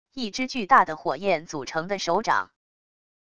一只巨大的火焰组成的手掌wav音频